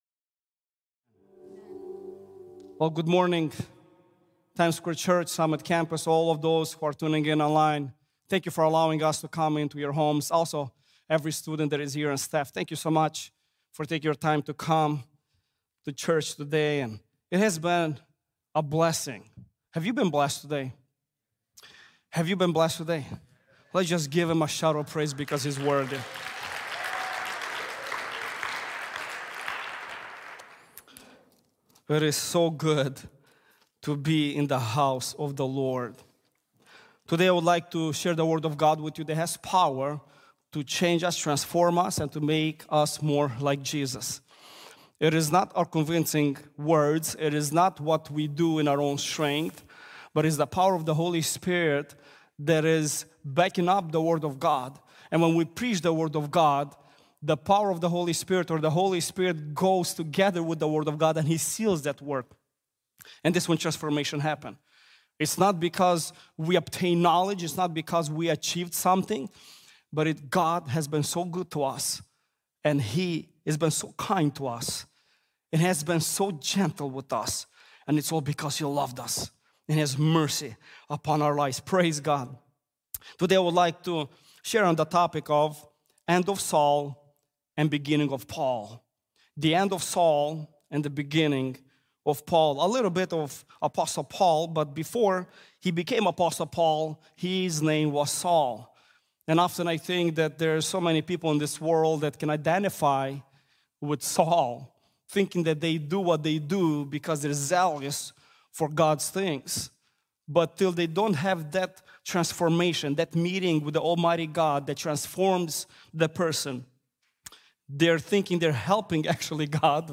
The End Of Saul and Beginning of Paul | Times Square Church Sermons